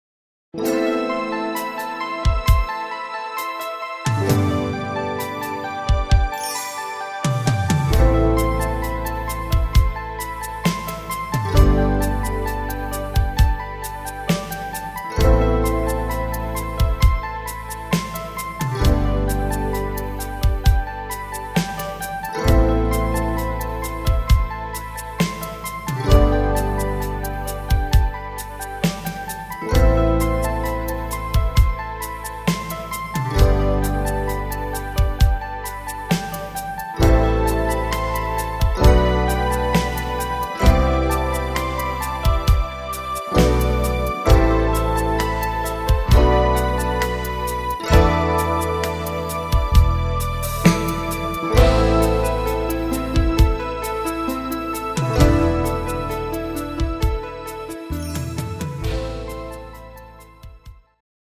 instr. Sax